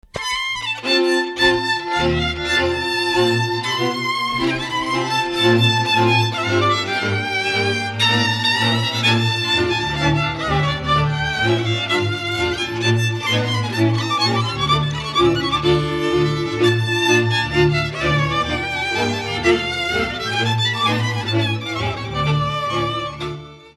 Dallampélda: Hangszeres felvétel
Erdély - Szilágy vm. - Szilágynagyfalu
vonószenekar
Műfaj: Cigány lassú csárdás
Stílus: 3. Pszalmodizáló stílusú dallamok